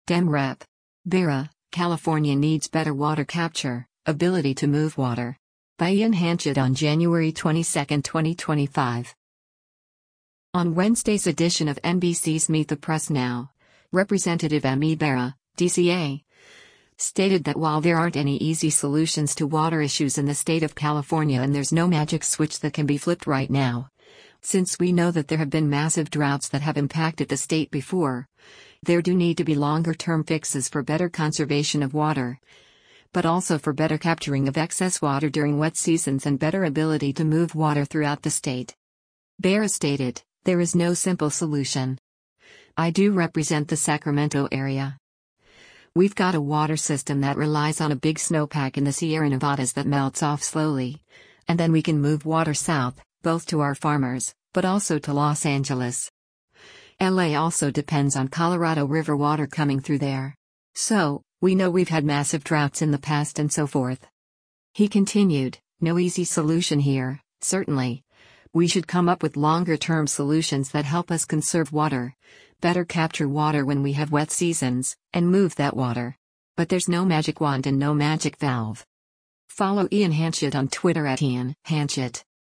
On Wednesday’s edition of NBC’s “Meet the Press Now,” Rep. Ami Bera (D-CA) stated that while there aren’t any easy solutions to water issues in the state of California and there’s no magic switch that can be flipped right now, since we know that there have been massive droughts that have impacted the state before, there do need to be longer-term fixes for better conservation of water, but also for better capturing of excess water during wet seasons and better ability to move water throughout the state.